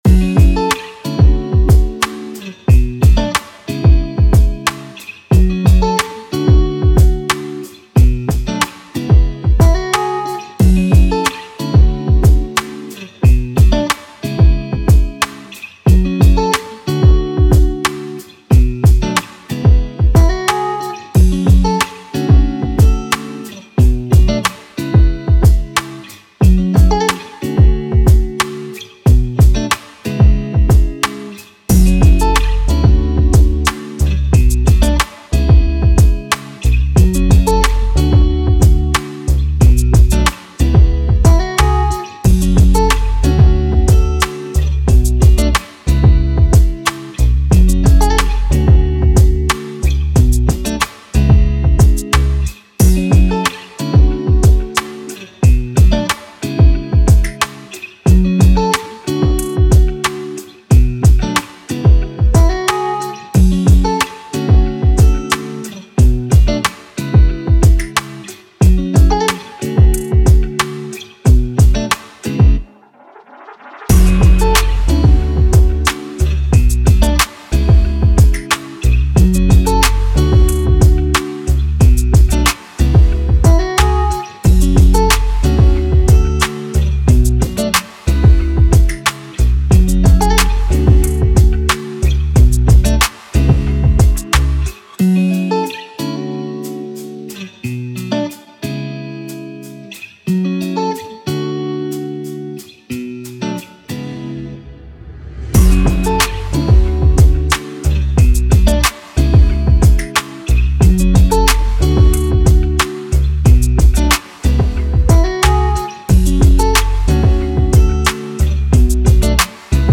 R&B, 90s
G minor